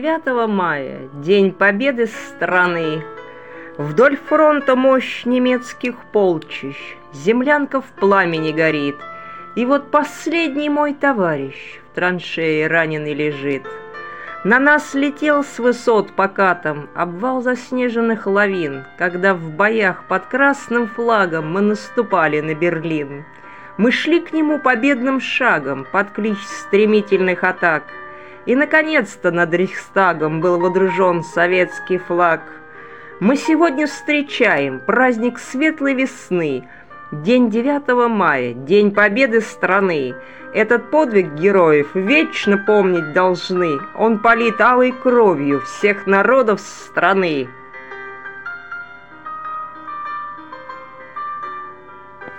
Музыка классики